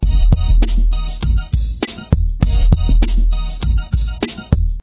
36 HeatBeats Song11 Break 100bpm.mp3